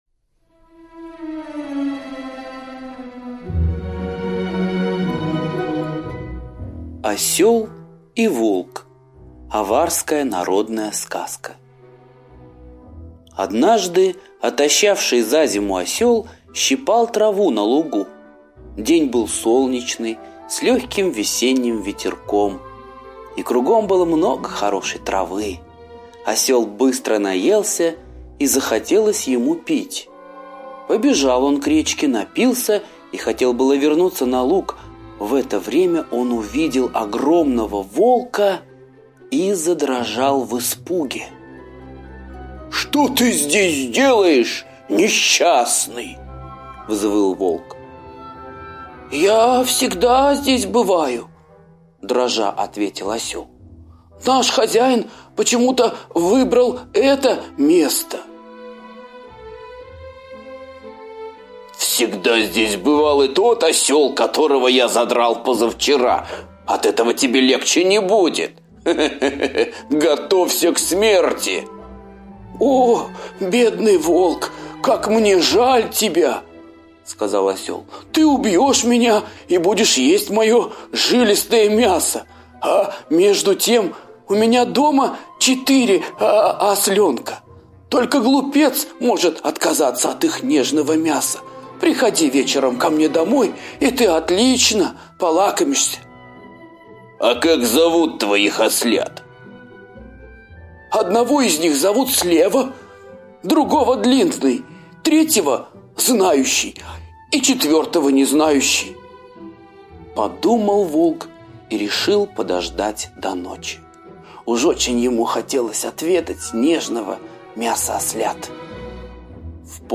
Осел и Волк – азиатская аудиосказка